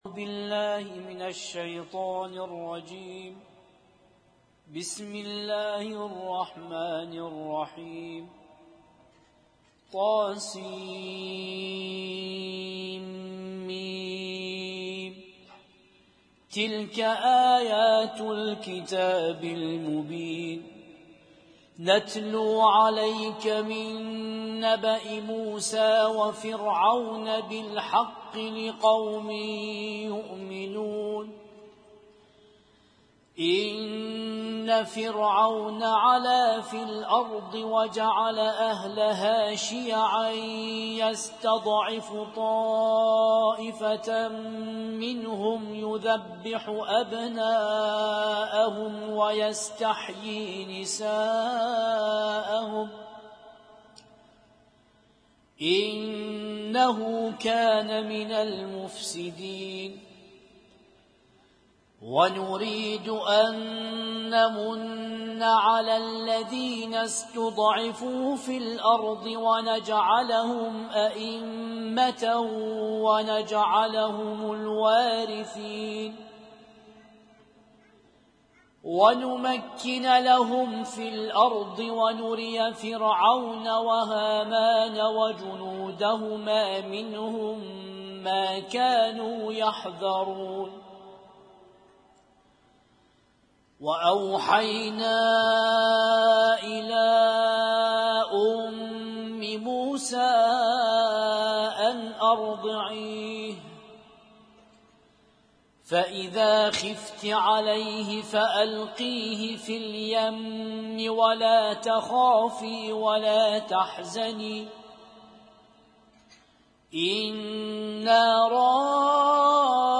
اسم النشيد:: ليلة 7 من شهر محرم الحرام 1441- ماتيسر من القرآن الكريم
اسم التصنيف: المـكتبة الصــوتيه >> القرآن الكريم >> القرآن الكريم - القراءات المتنوعة